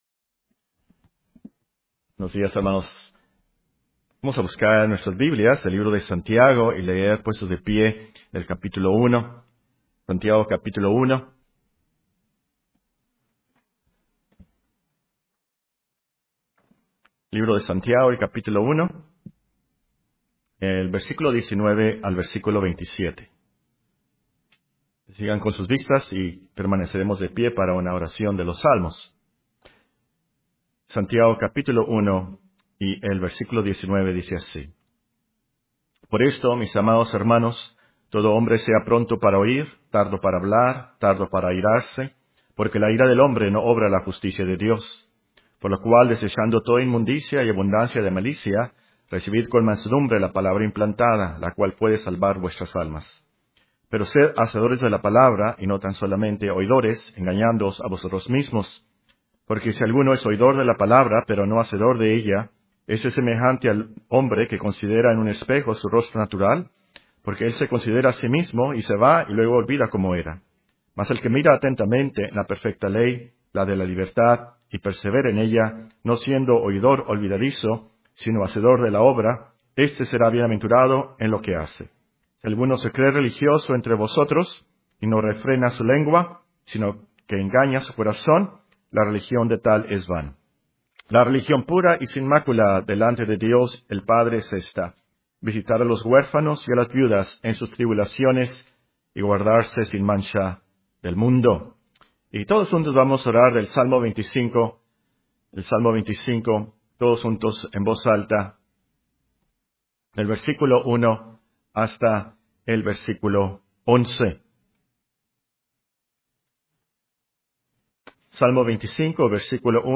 Sermones Biblicos Reformados en Audio, porque la Fe viene por el Oir